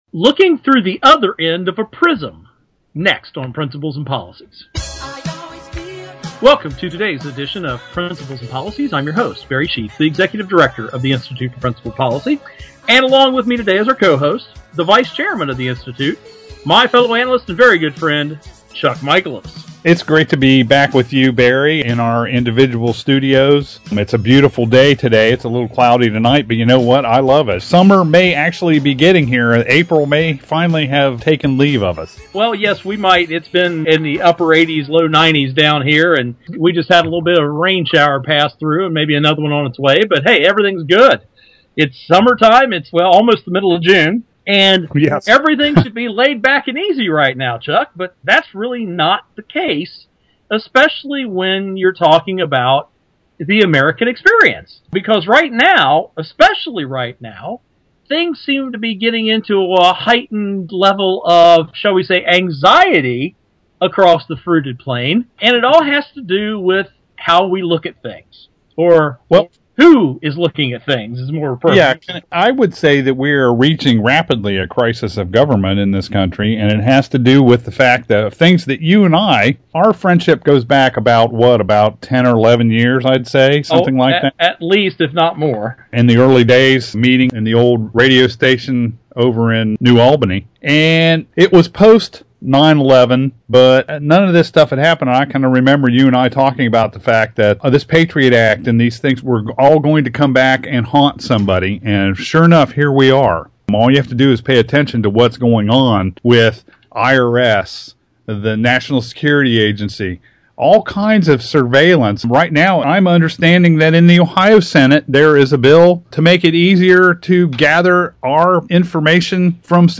Our Principles and Policies radio show for Monday June 10, 2013.